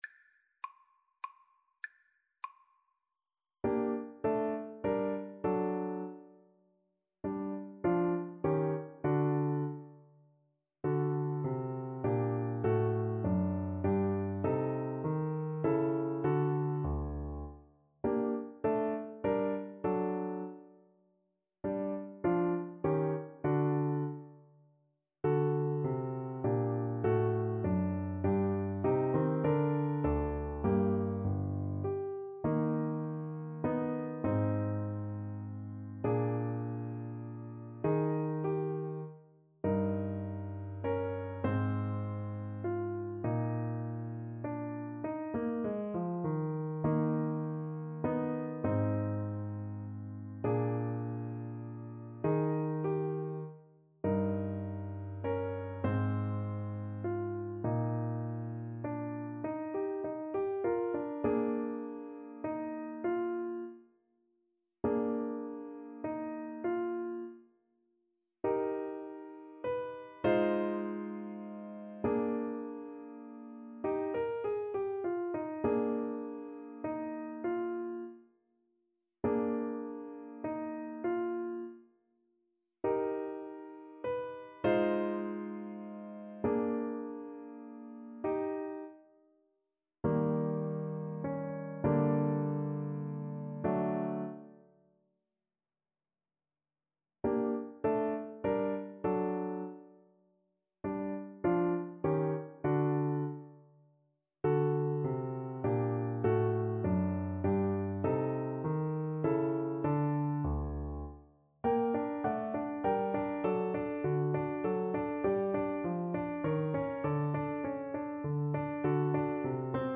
Allegretto
3/4 (View more 3/4 Music)
G minor (Sounding Pitch) A minor (Clarinet in Bb) (View more G minor Music for Clarinet )
Classical (View more Classical Clarinet Music)